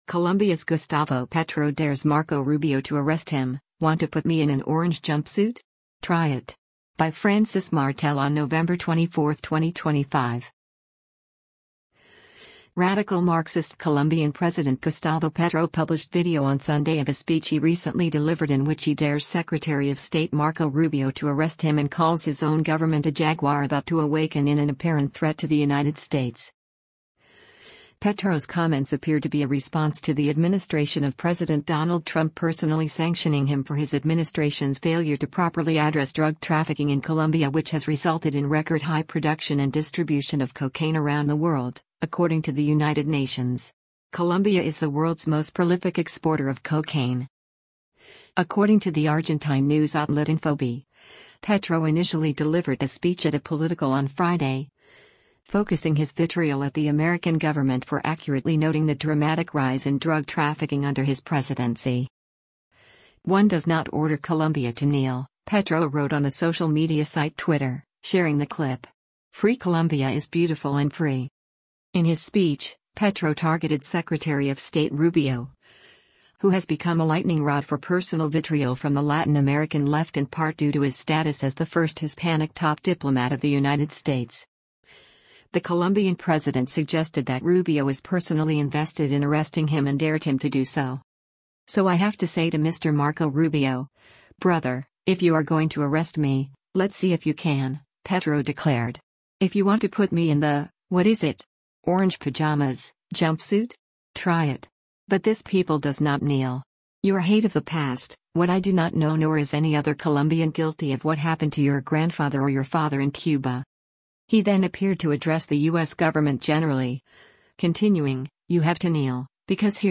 Radical Marxist Colombian President Gustavo Petro published video on Sunday of a speech he recently delivered in which he dares Secretary of State Marco Rubio to arrest him and calls his own government a “jaguar about to awaken” in an apparent threat to the United States.
According to the Argentine news outlet Infobae, Petro initially delivered the speech at a political on Friday, focusing his vitriol at the American government for accurately noting the dramatic rise in drug trafficking under his presidency.